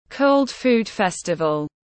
Tết Hàn Thực tiếng anh gọi là Cold Food Festival, phiên âm tiếng anh đọc là /kəʊld fuːd ˈfɛstəvəl/
Cold-Food-Festival-.mp3